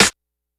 snr_13.wav